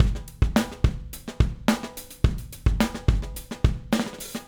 Retro Funkish Beat 01 Fill A.wav